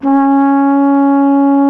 TENORHRN C#2.wav